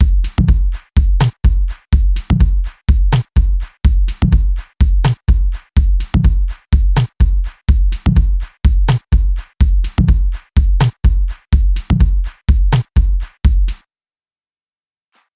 非洲人 房子鼓循环A
描述：我在Studio One中设计的声学汤姆和踢腿鼓样本...
Tag: 125 bpm Dance Loops Drum Loops 2.58 MB wav Key : Unknown